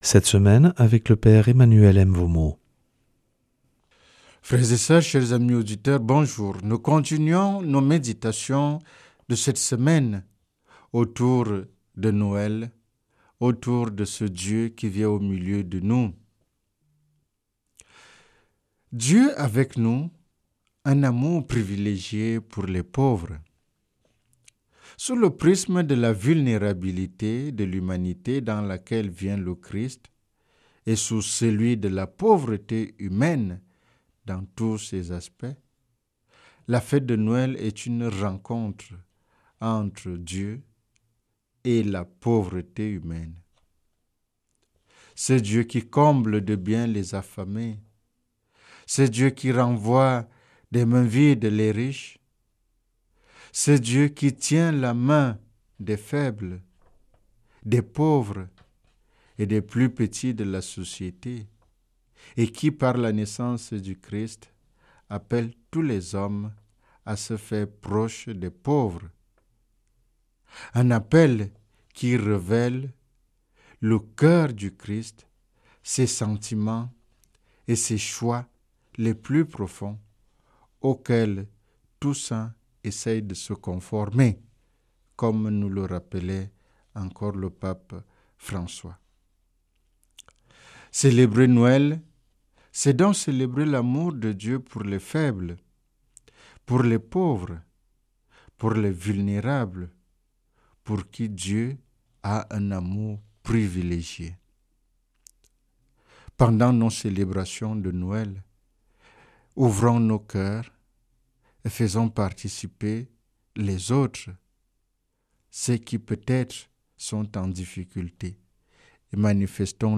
mercredi 24 décembre 2025 Enseignement Marial Durée 10 min